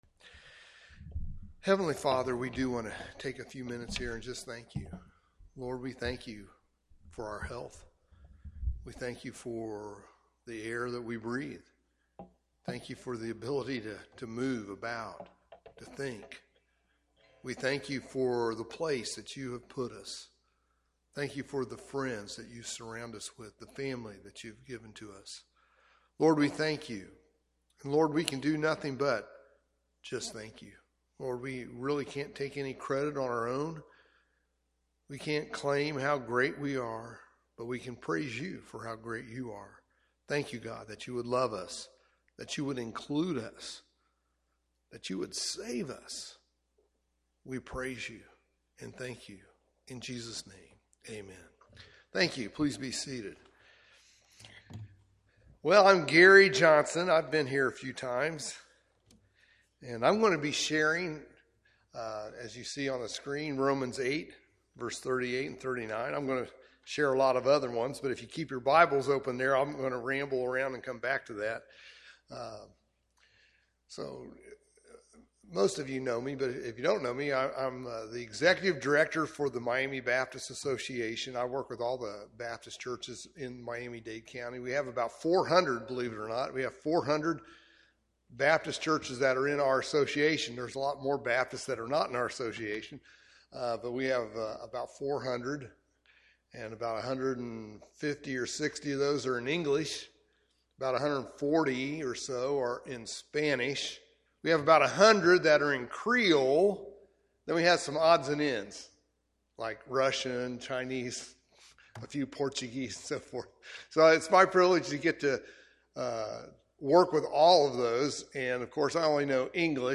From Series: "English Sermons"